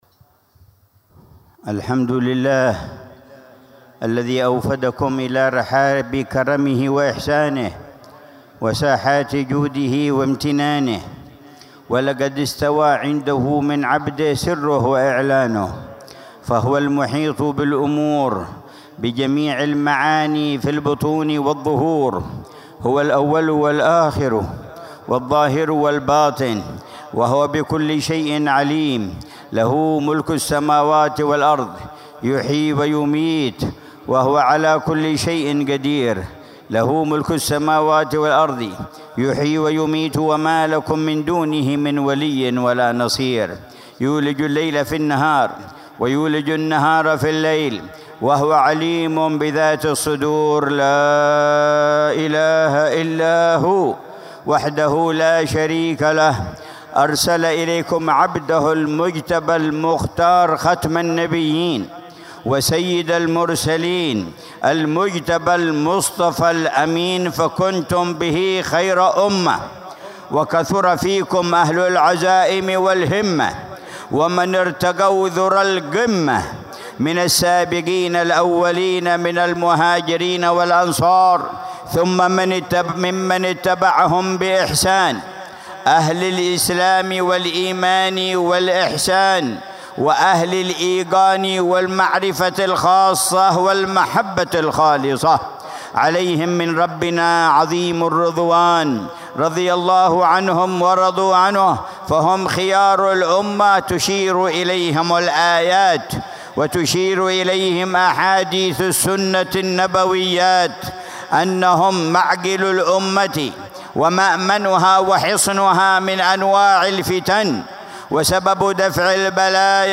مذاكرة العلامة الحبيب عمر بن محمد بن حفيظ في مجلس الوعظ والتذكير في زيارة الإمام الشيخ أبي بكر بن سالم للنبي هود عليه السلام، شرق وادي حضرموت، ضحى الأحد 10 شعبان 1446هـ بعنوان: